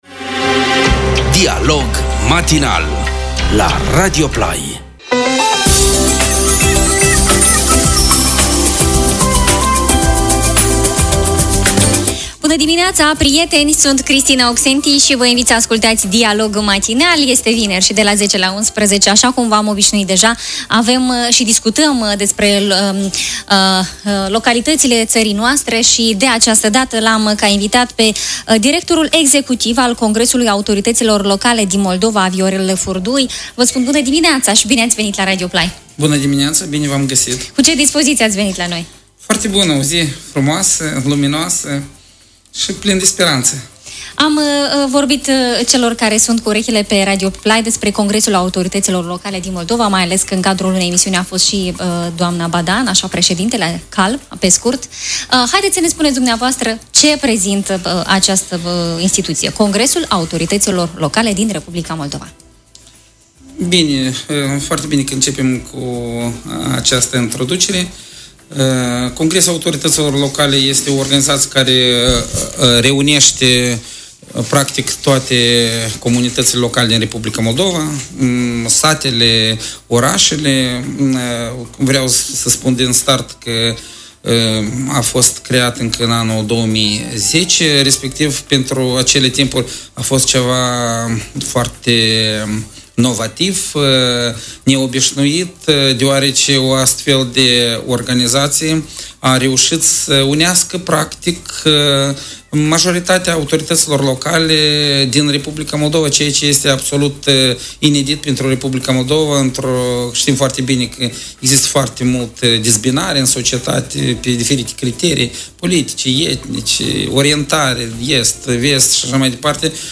Emisiunea radio La 21 martie, Congresul Autorităților Locale din Moldova (CALM) a împlinit 8 ani de la înființare.